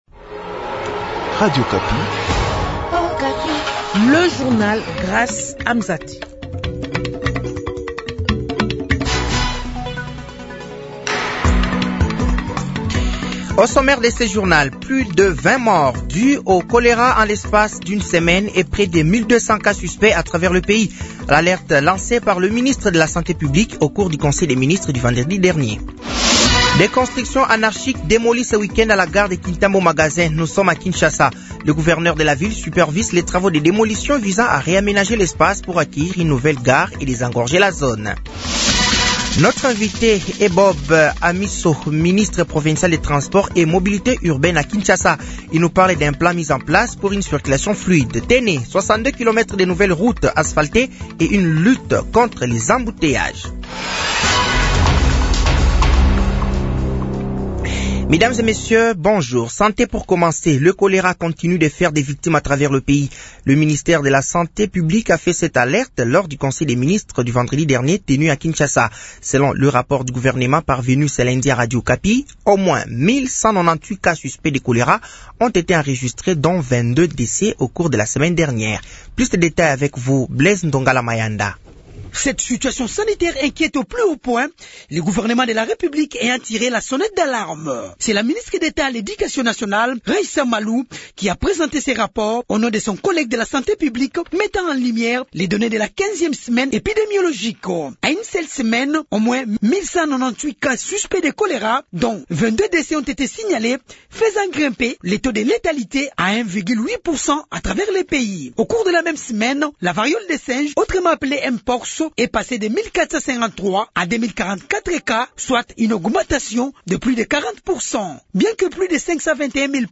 Journal français de 15h de ce lundi 28 avril 2025